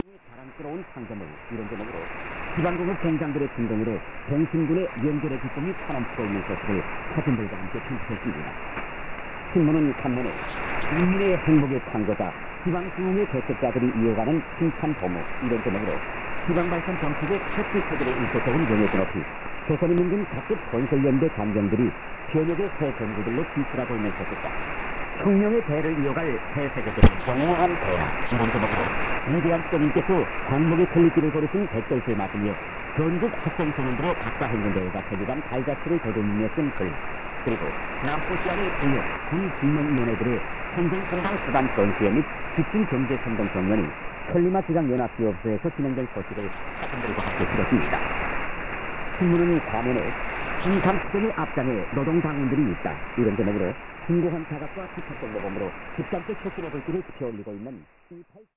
RX: LOWE HF-150
antenna: HiQBBA